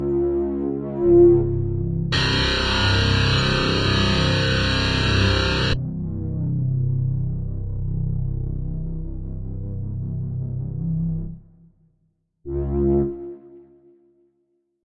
鼓 贝斯吉他 " 贝斯吉他 合成器
描述：由FL工作室创作的低音吉他合成音